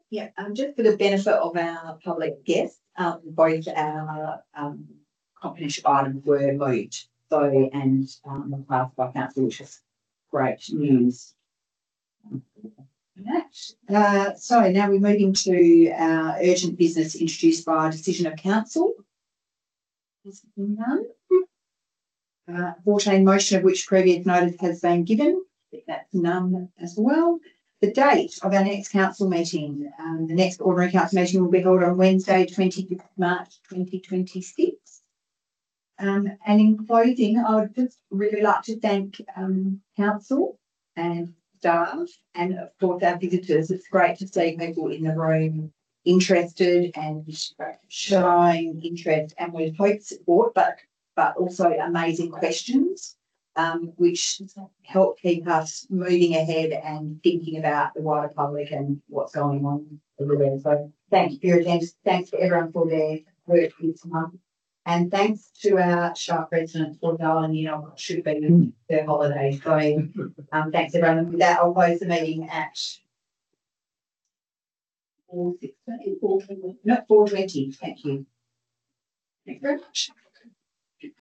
(Audio) Ordinary Council Meeting 25 February 2026 Recording PART 2 PUBLIC (1.88 MB)